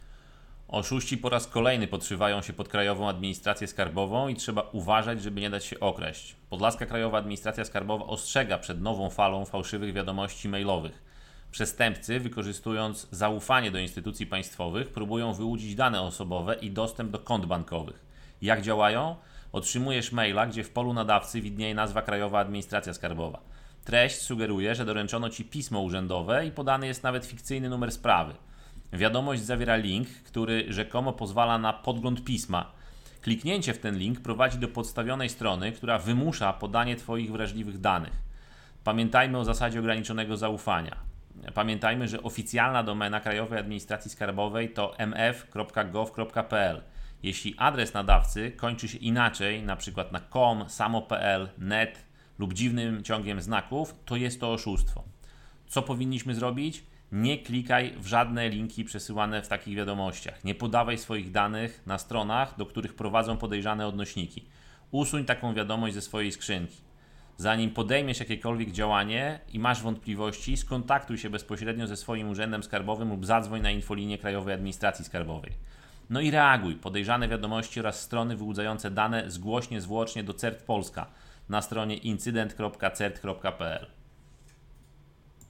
Nie daj się okraść - wypowiedź